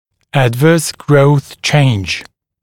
[‘ædvɜːs grəuθ ʧeɪnʤ] [æd’vɜːs][‘эдвё:с гроус чэйндж] [эд’вё:с]негативное изменение в процессе роста